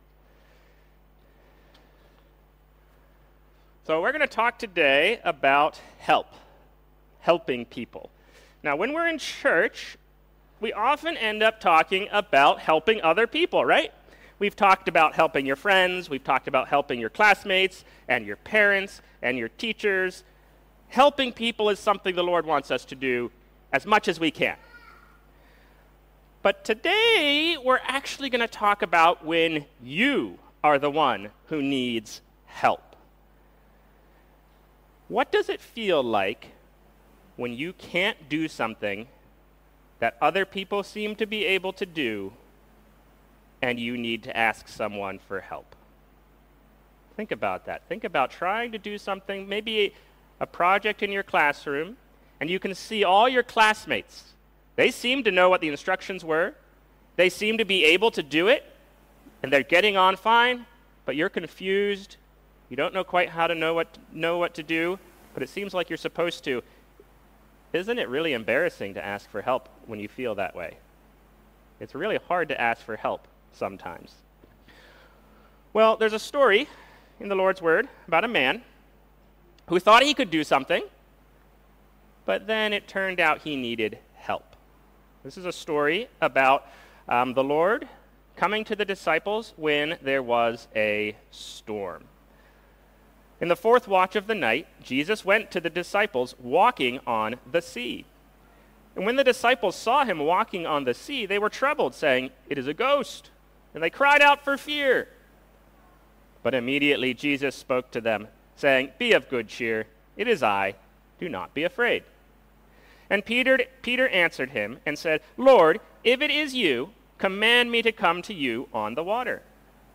Recordings of weekly sermons at New Church Westville, a family centred New Christian church located in Westville, South Africa.